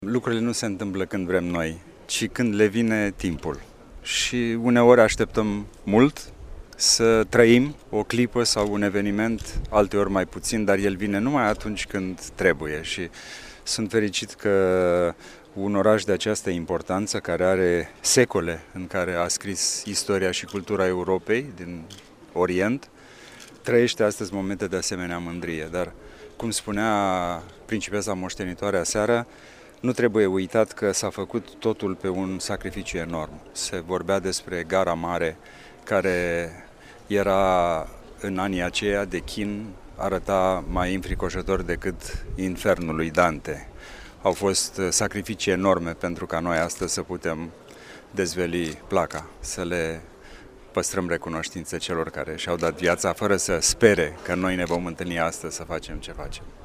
Pricipele Radu  a fost, apoi, prezent la dezvelirea plăcii comemorative aşezată pe Muzeul Unirii, fosta reşedinţă a Regelui Ferdinand I, din timpul Primului Război Mondial. Principele Radu a declarat că trebuie să purtăm recunoştinţă acelora care s-au jertfit pentru patrie: